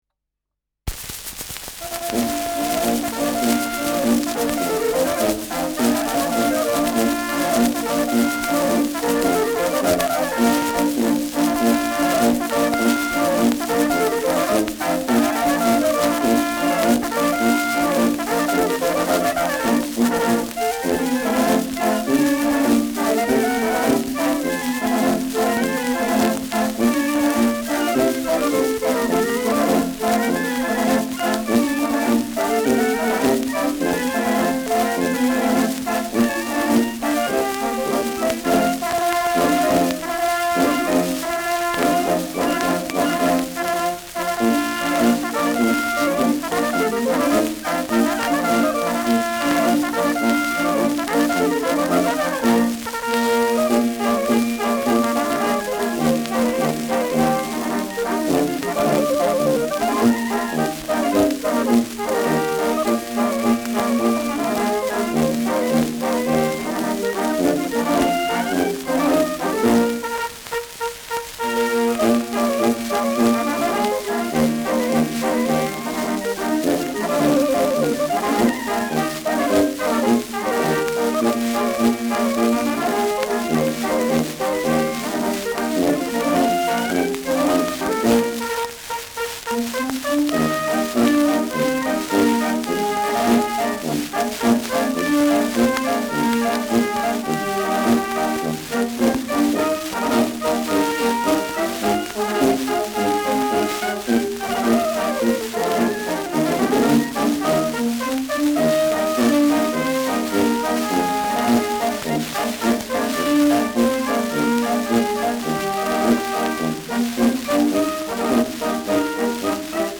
Schellackplatte
präsentes Rauschen : Knistern
[München] (Aufnahmeort)